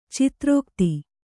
♪ citrōkti